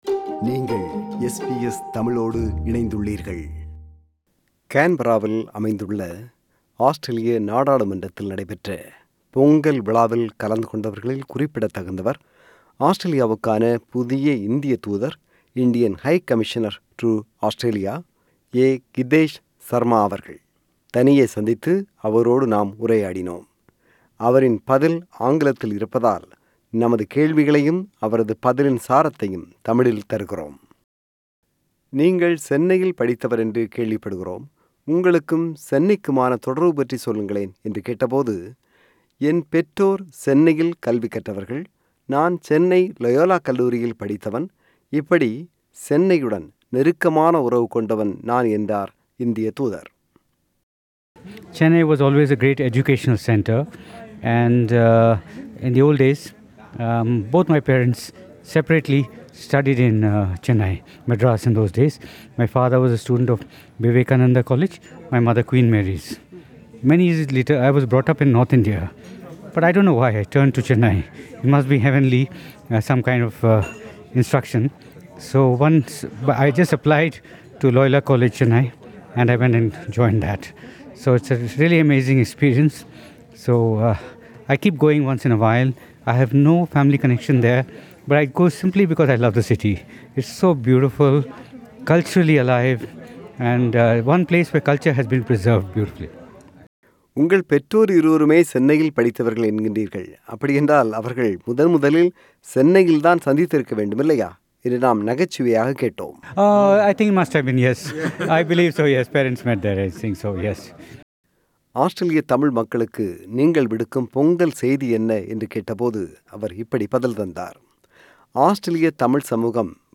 ஆஸ்திரேலியாவுக்கான புதிய இந்தியத் தூதராக பதவியேற்றிருப்பவர் மதிப்புக்குரிய A. Gitesh Sarma அவர்கள். கடந்தவாரம் கேன்பராவில் ஆஸ்திரேலிய நாடாளுமன்றத்தில் இடம்பெற்ற பொங்கல்விழாவின்போது நாம் அவரைச் சந்தித்து உரையாடினோம்.